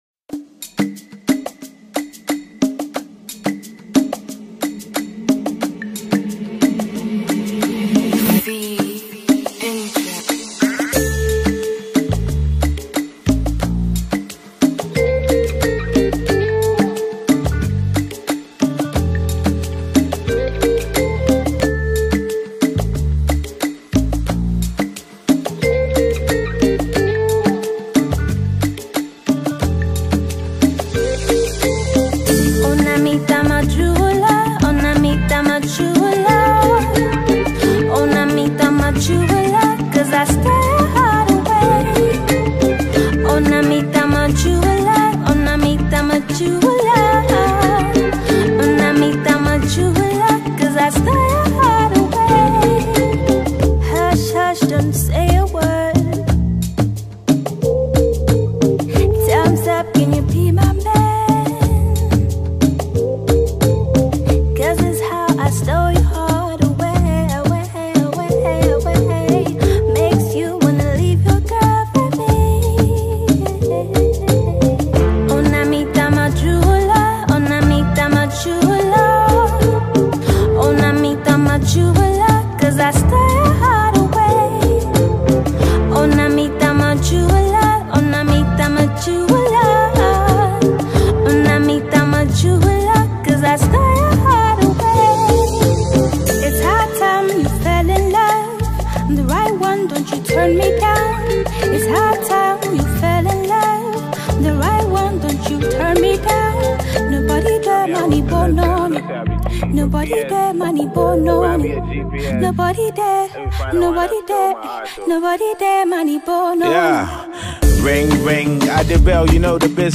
melodious and harmonious Ghanaian female vocalist